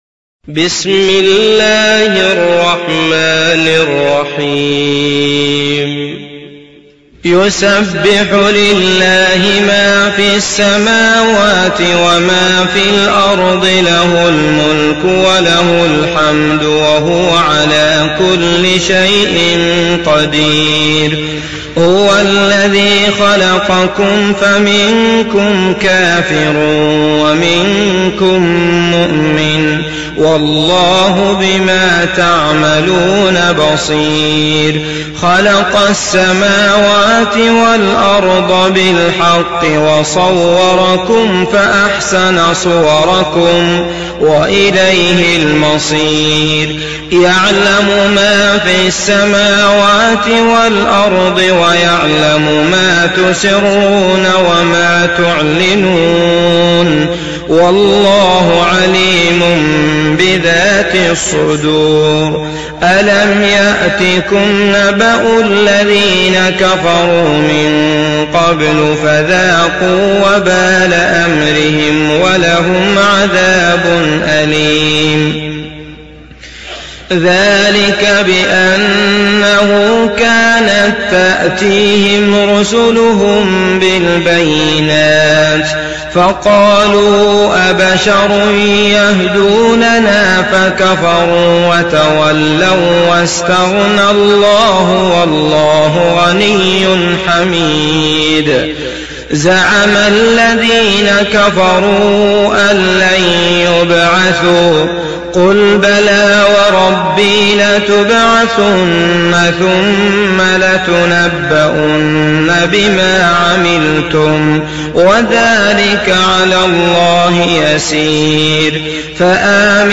تحميل سورة التغابن mp3 عبد الله المطرود (رواية حفص)